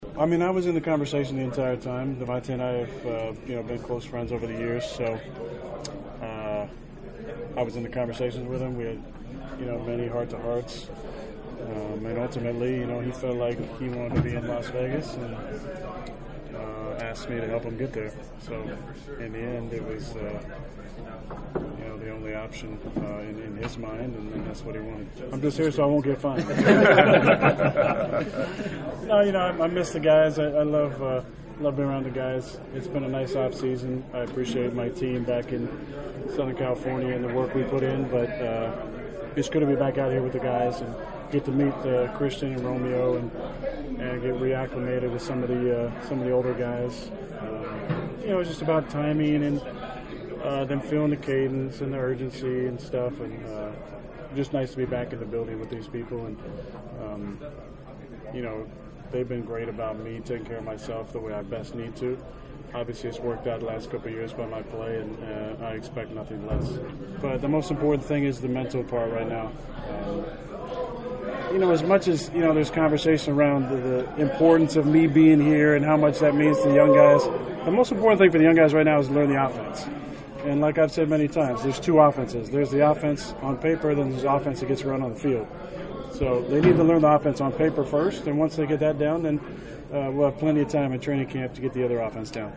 After practice was the first time reporters surrounded Rodgers’ locker for a Q and A since before the NFC Divisional Playoff loss to San Francisco.